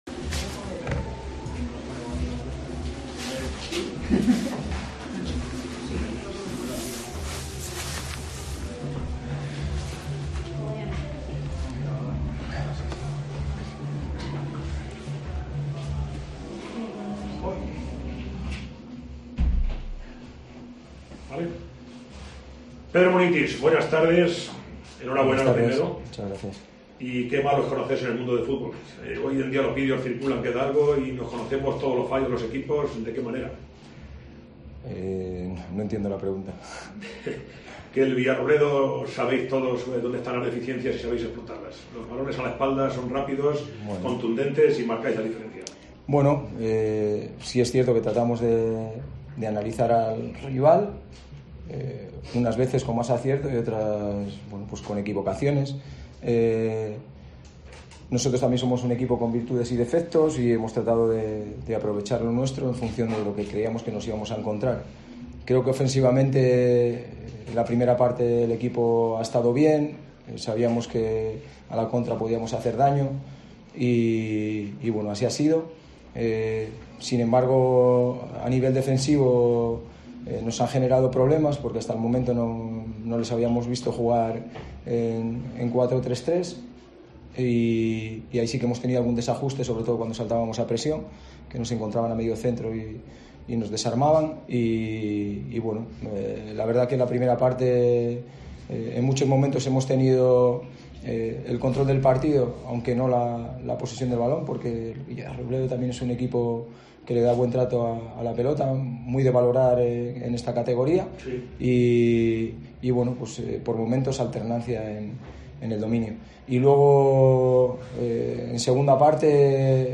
Rueda de prensa de Munitis tras el Villarrobledo 1 - CD Badajoz 3